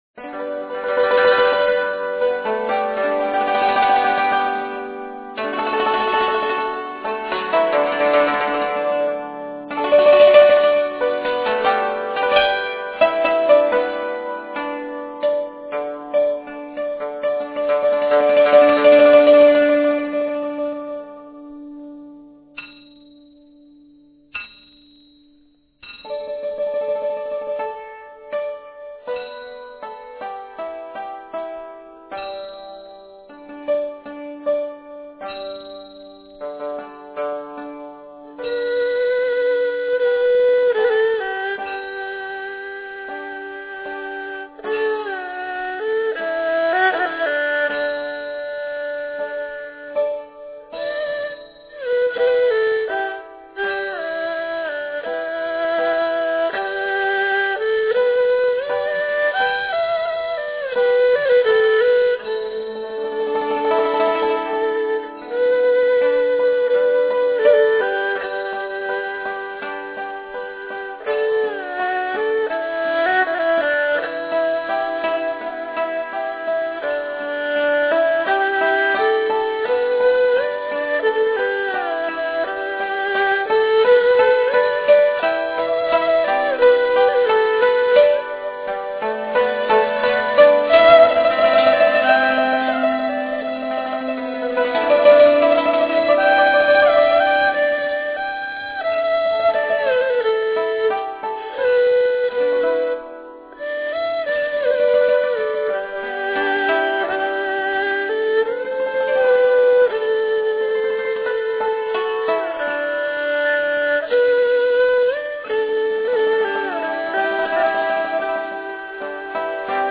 Čínská tradiční hudba
Některé skladby byly nahrány příležitostně ze starších nosičů či z rozhlasu, takže kromě poněkud snížené technické kvality se mi k nim nedochovaly názvy a údaje o interpretaci - omlouvám se za neúplnost, popř. zkreslené či zkomolené názvy...